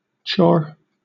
IPA/tʃɔː/, IPA/tʃoʊr/